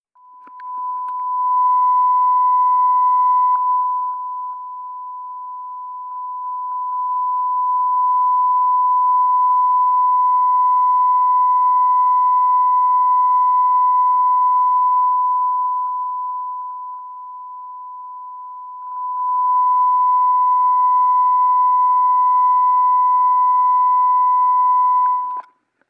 The output levels in the right channel are higher and the peak level is around 0.5 (or -6 dB).